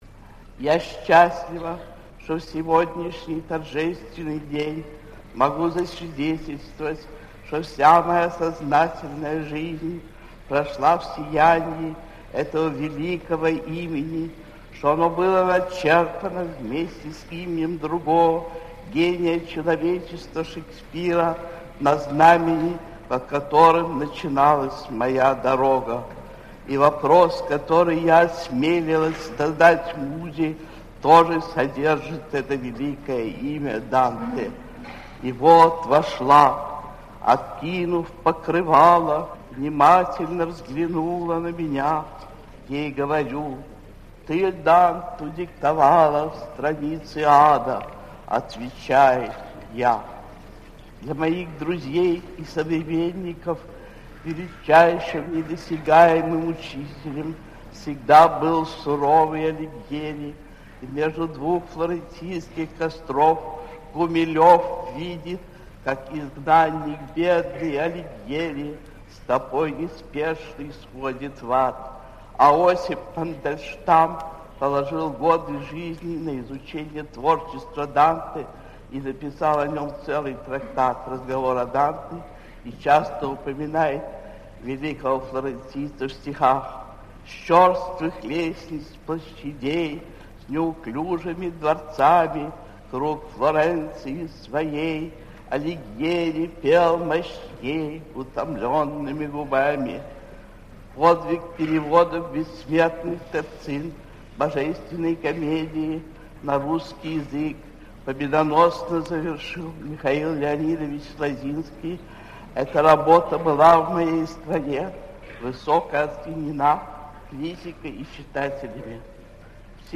1. «Анна Ахматова – Слово о Данте (читает автор)» /
anna-ahmatova-slovo-o-dante-chitaet-avtor